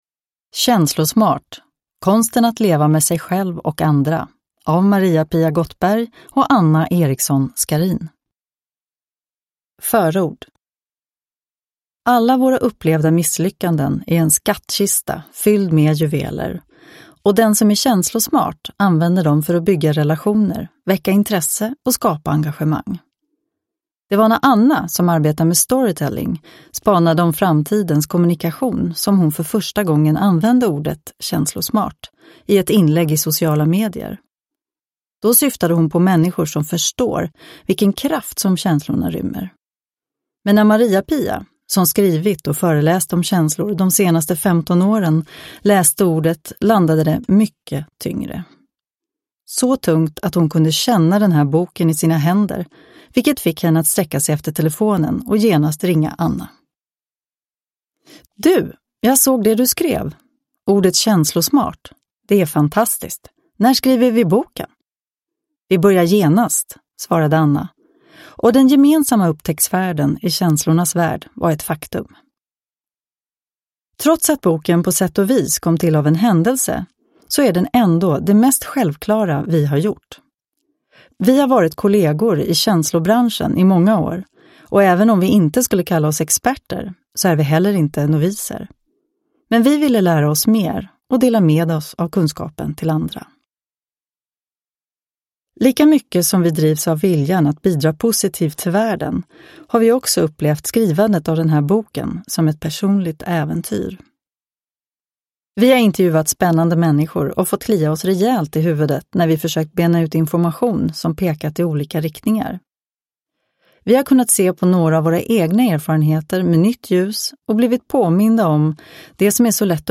Känslosmart : konsten att leva med sig själv och andra – Ljudbok – Laddas ner